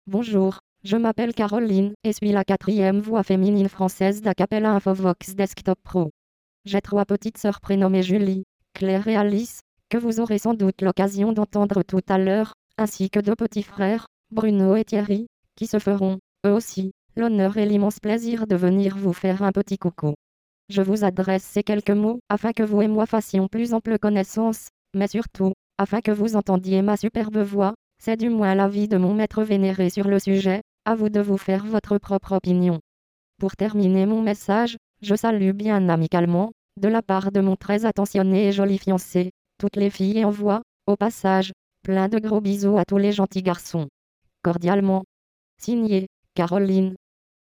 Texte de démonstration lu par Caroline, quatrième voix féminine française d'Acapela Infovox Desktop Pro
Écouter la démonstration de Caroline, quatrième voix féminine française d'Acapela Infovox Desktop Pro